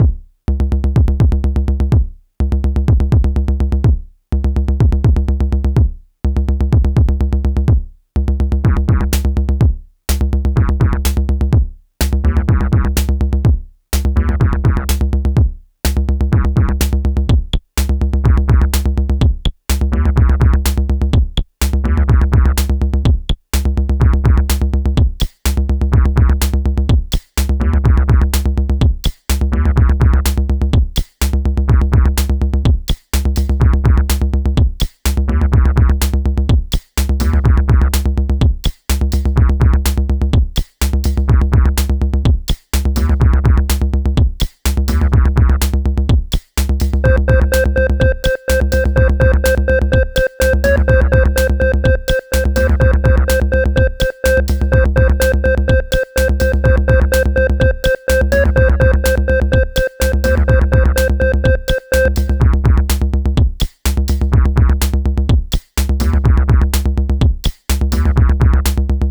Pieza de Electroclash
Música electrónica
melodía
repetitivo
rítmico
sintetizador